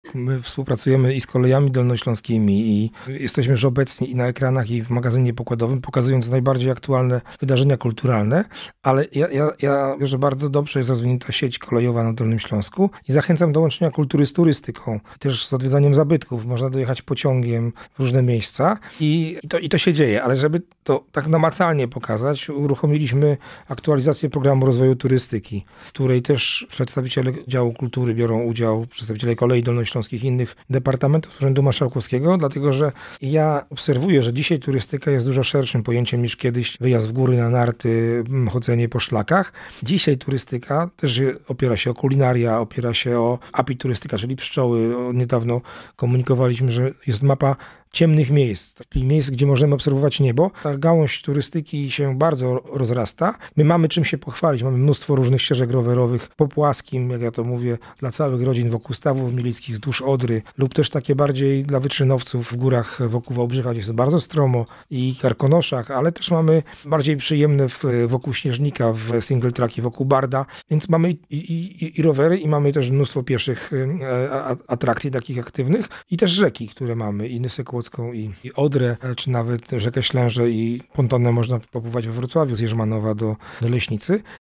Mówi Krzysztof Maj – Członek Zarządu Województwa Dolnośląskiego.